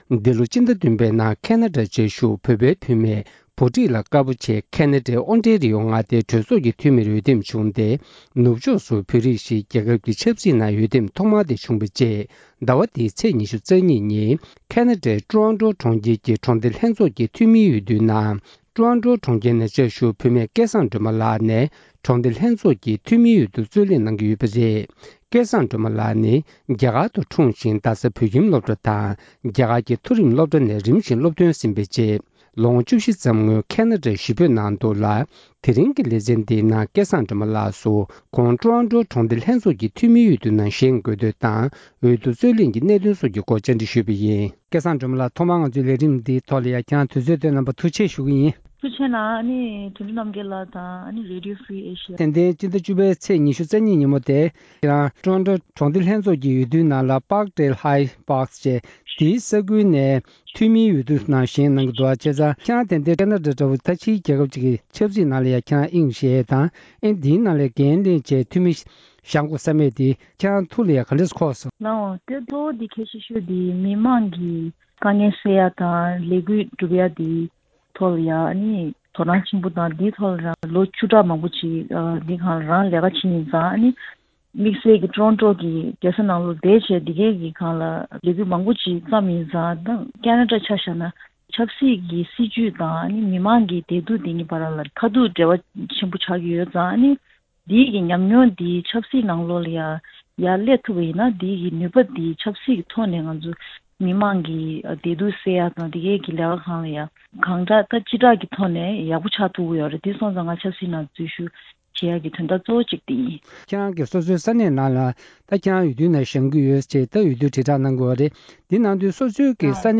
བཅར་འདྲི།